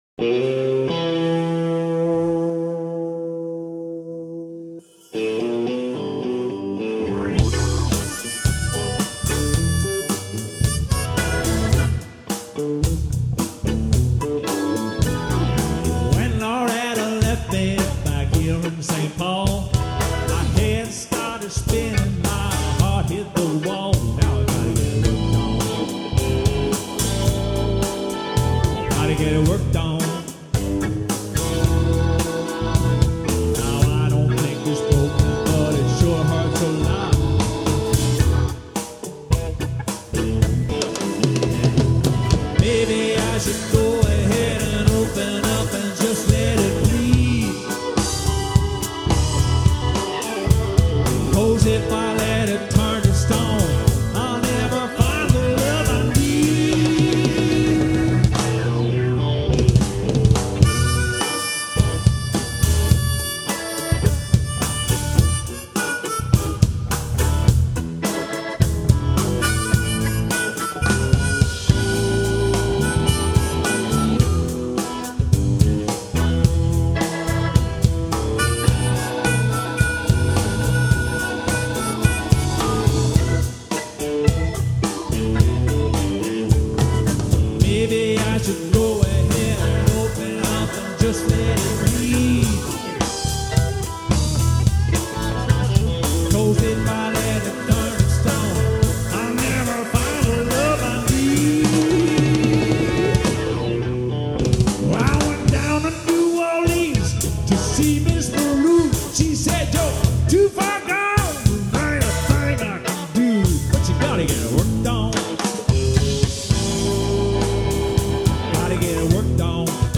– Rockmusik –
Gekürzte Live-Mitschnitte
(ab Mixer-Ausgang)